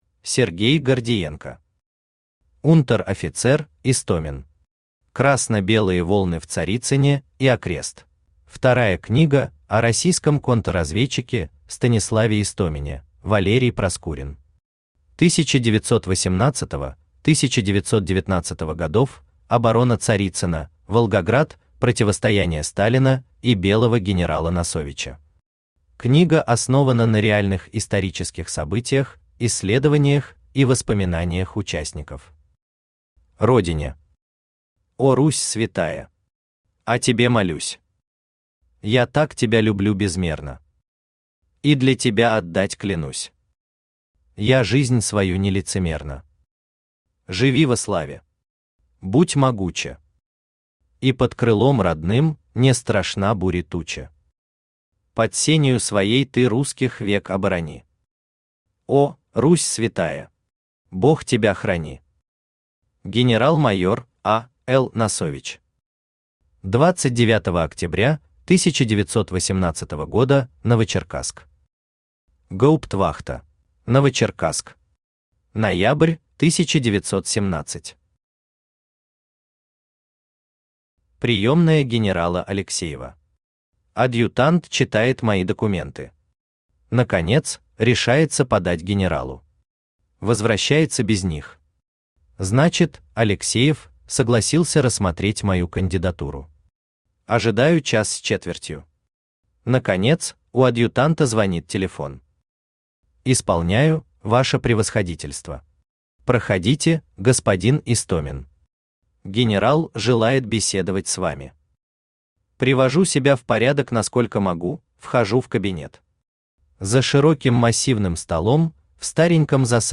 Аудиокнига Унтер-офицер Истомин. Красно-белые волны в Царицыне и окрест | Библиотека аудиокниг
Красно-белые волны в Царицыне и окрест Автор Сергей Гордиенко Читает аудиокнигу Авточтец ЛитРес.